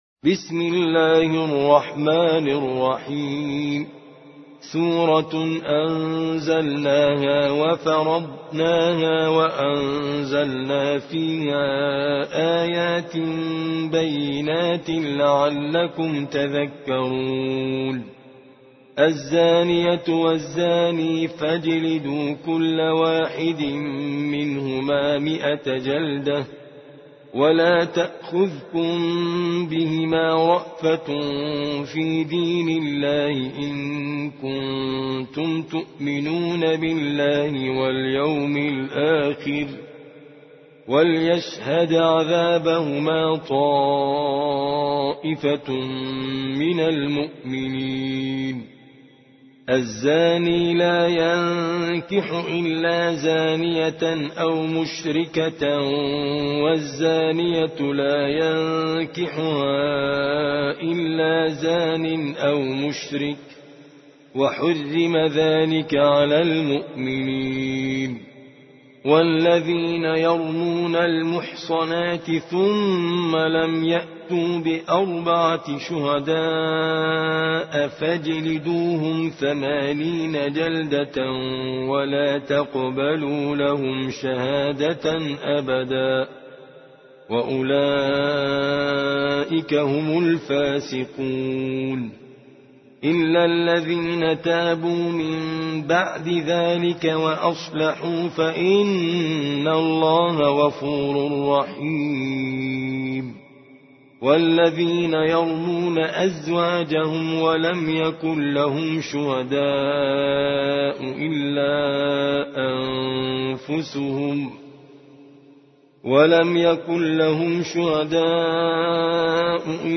سورة النور / القارئ